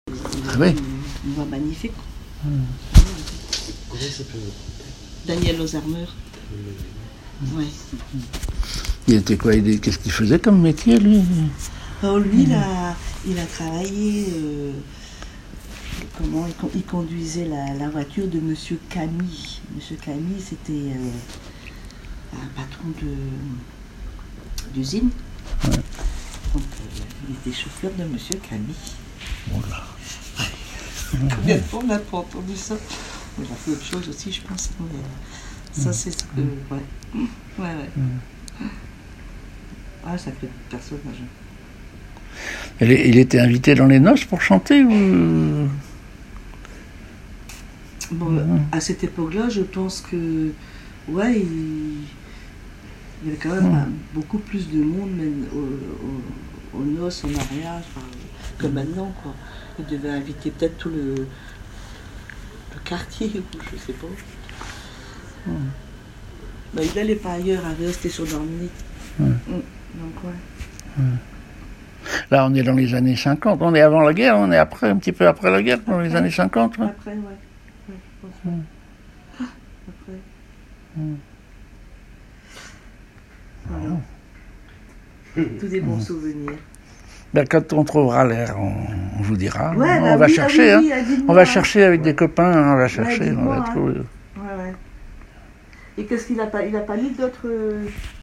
Un chanteur
Catégorie Témoignage